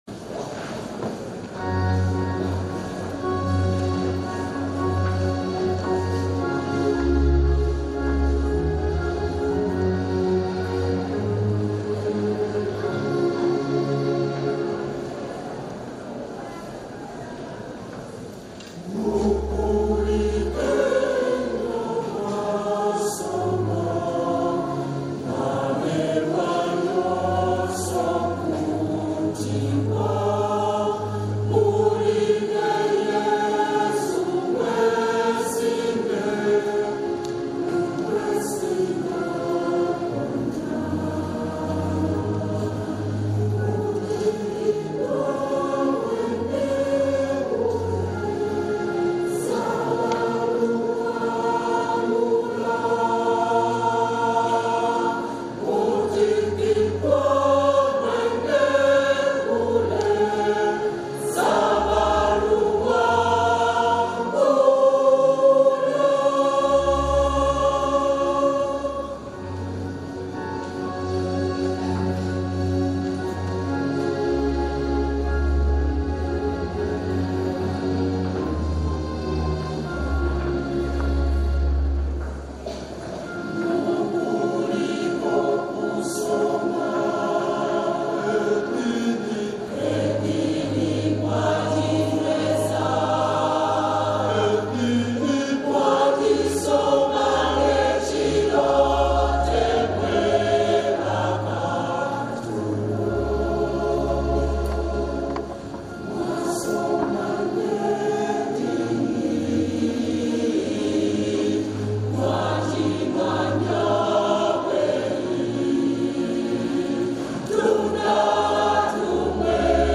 The melody is catchy and upbeat, creating a joyful mood.